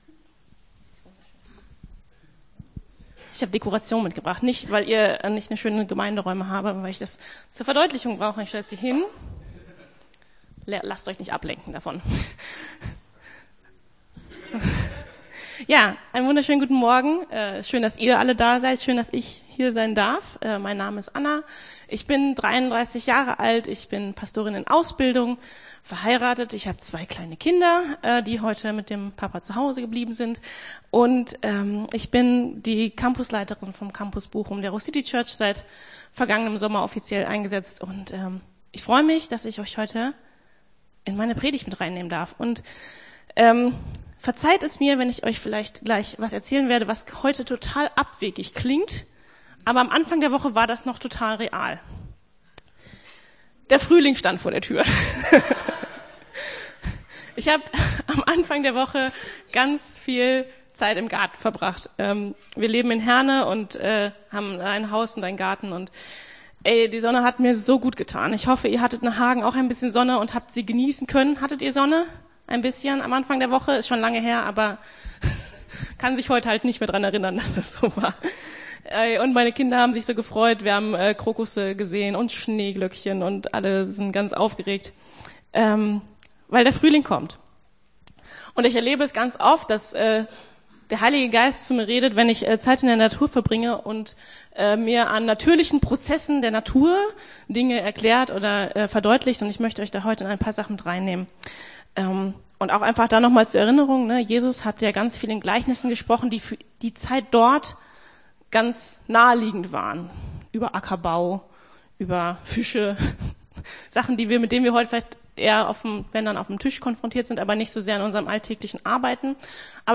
Gottesdienst 19.02.23 - FCG Hagen